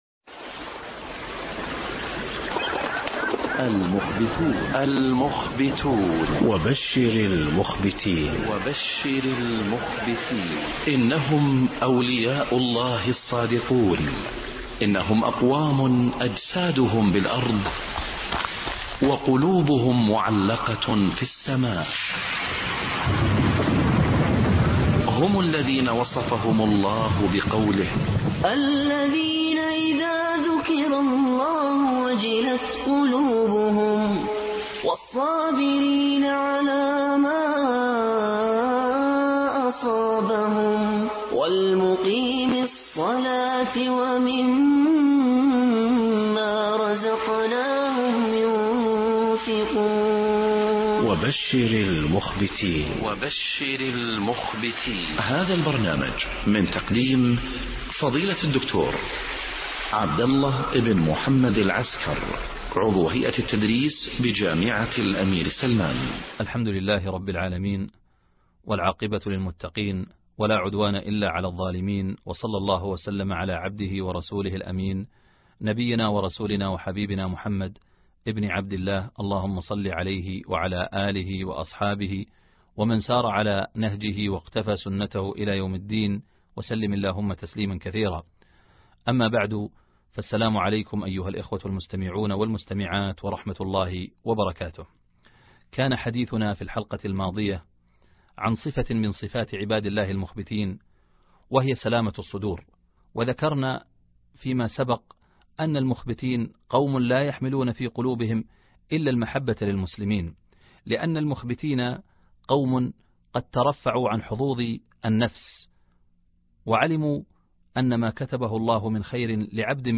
الدرس 20 عوامل تنقية القلوب2 (وبشر المخبتين)